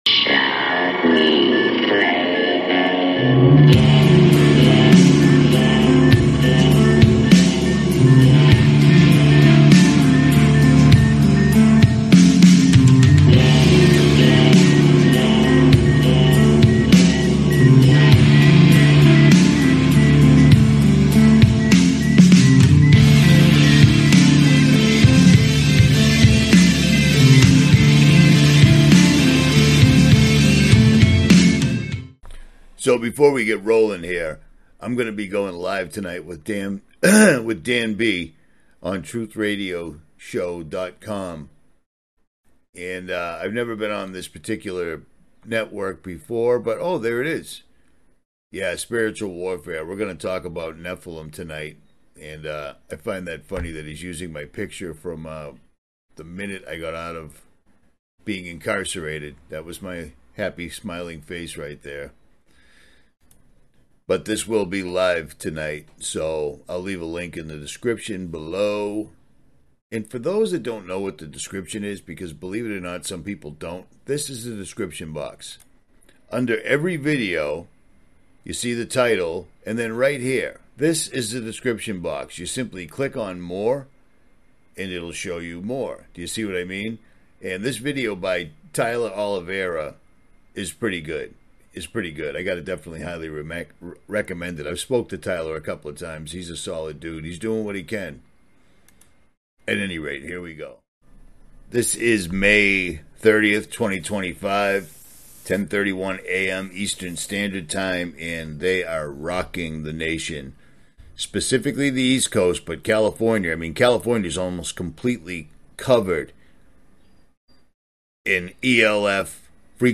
Join us for a live show where the speaker explores mysterious events like the moon's unusual movements and strange sky footage. Discover how harmful frequencies might impact the East Coast and California.